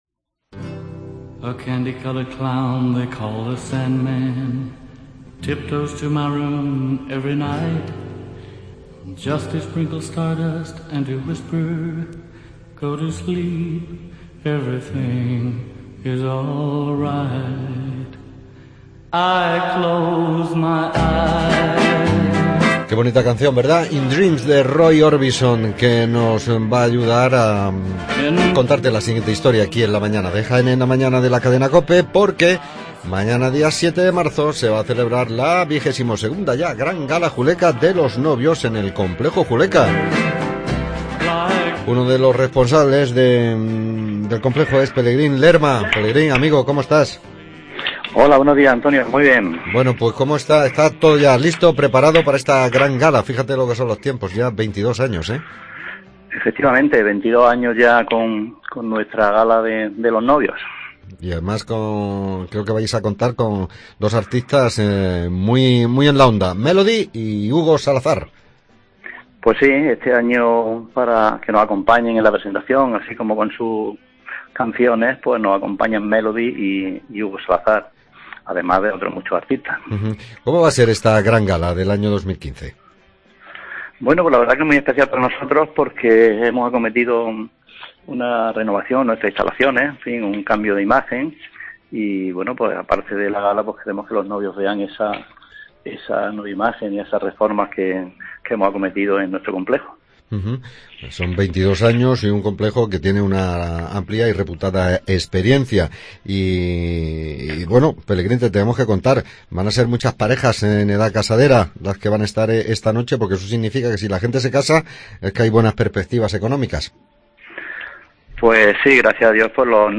ENTREVISTA GALA NOVIOS JULECA